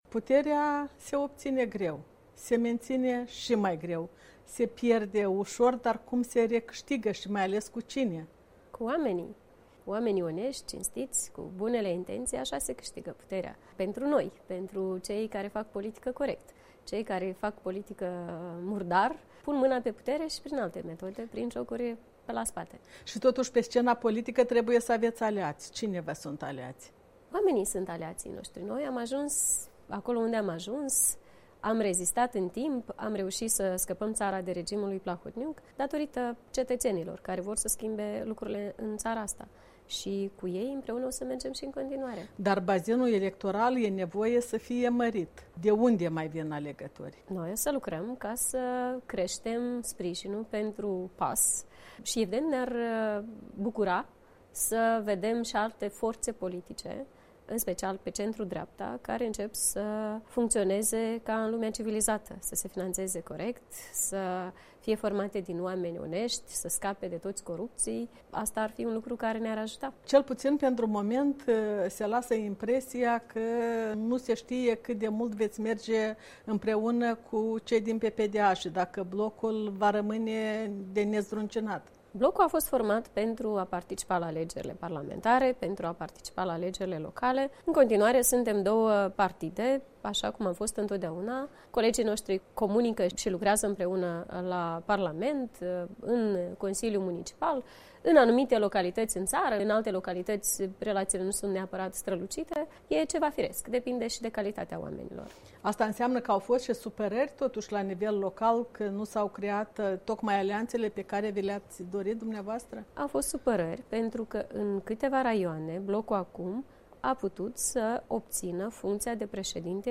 Interviu cu președinta Partidului Acțiune și Solidaritate, despre viitoarele planuri ale formațiunii și acțiunile guvernului condus de Ion Chicu.